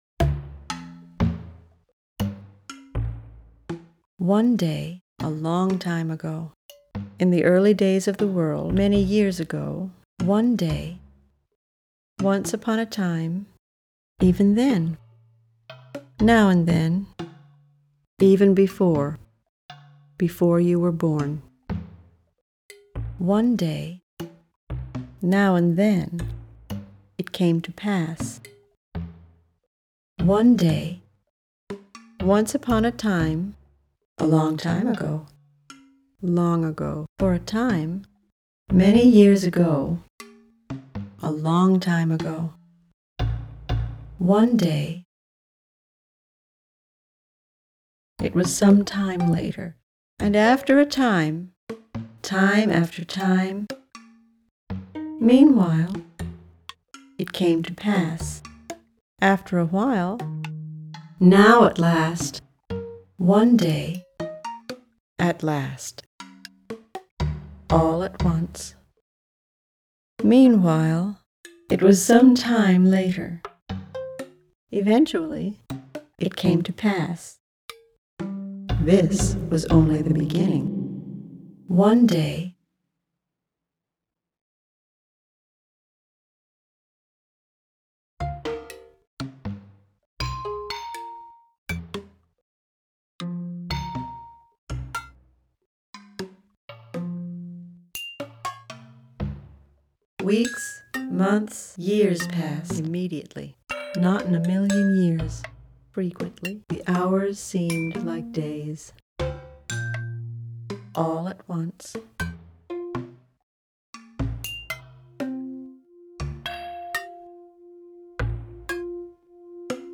is one of the pioneers of computer music.